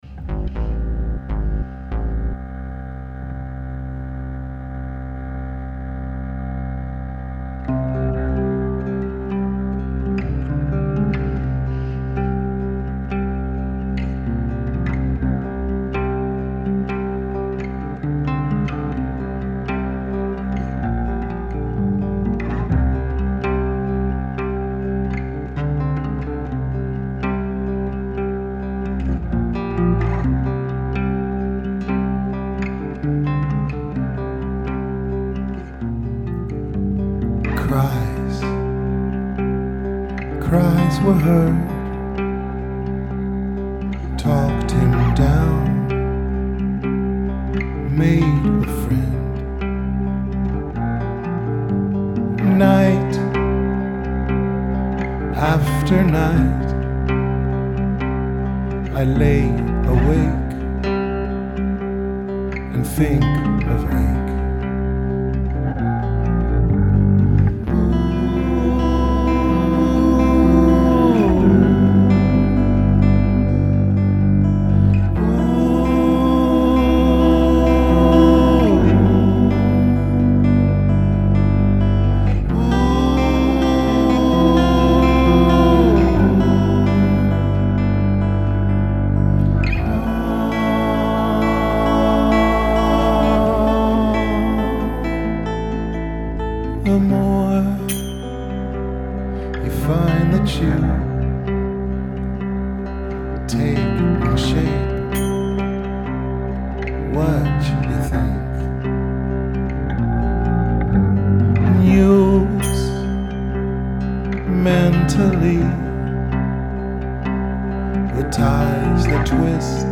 Rehearsals 3.3.2012